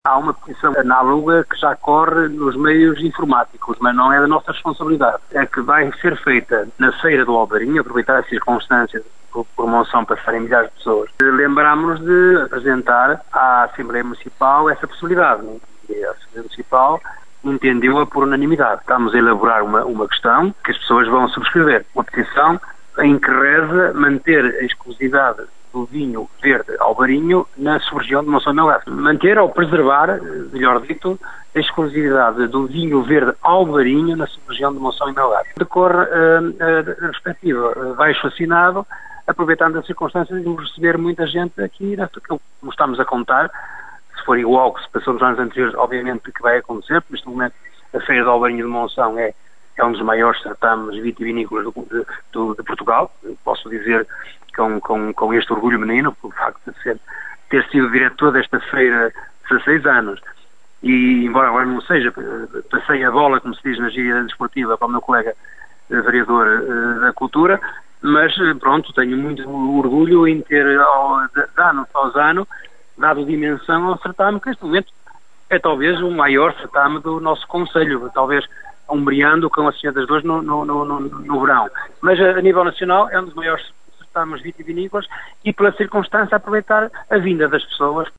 O presidente da Câmara de Monção, Augusto Domingues, avançou à Rádio Caminha que estão a ser preparadas outras formas de luta para tentar travar o alargamento da produção do Alvarinho à região norte do país, mas prefere não revelar ainda a próxima jogada.
moncao-augusto-domingues-peticao-alvarinho-1.mp3